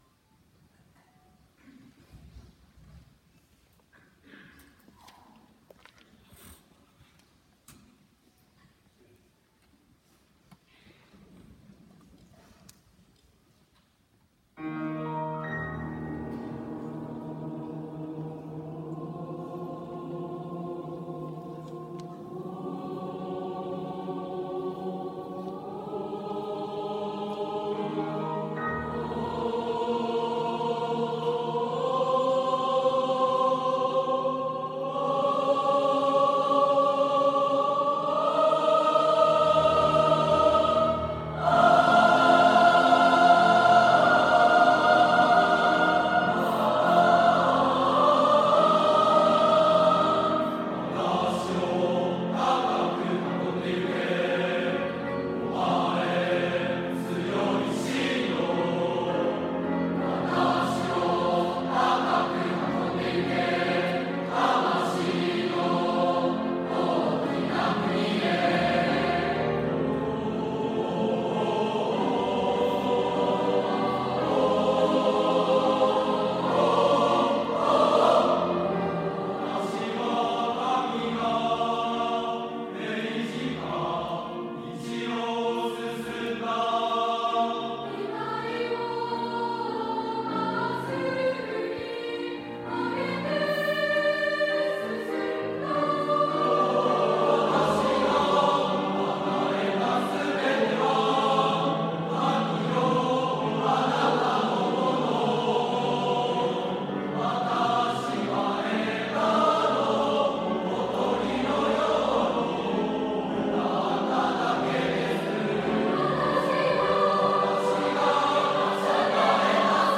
合唱祭が行われました - 羽島中BLOG | 羽島市立羽島中学校